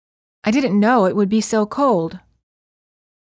• ストレスをかける単語の主要な母音を長く発音する
※当メディアは、別途記載のない限りアメリカ英語の発音を基本としています